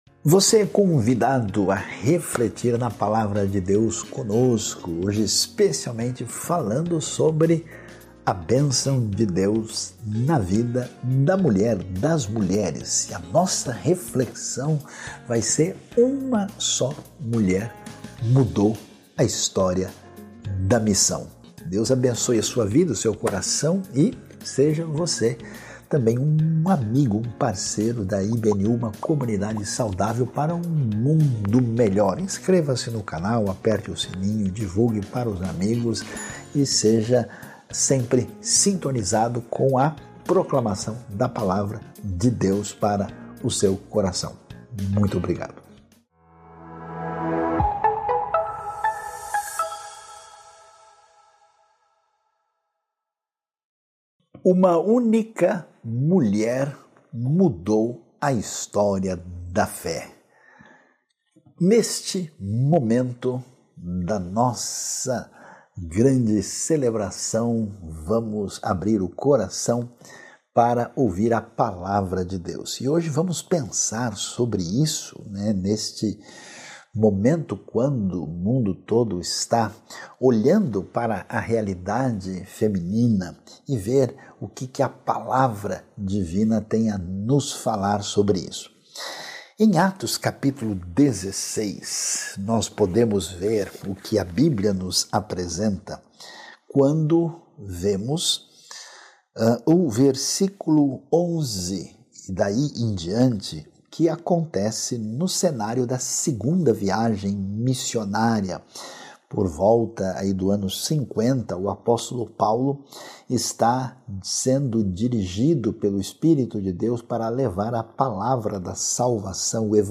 Mensagem
na Igreja Batista Nações Unidas.